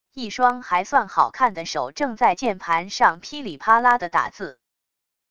一双还算好看的手正在键盘上噼里啪啦的打字wav音频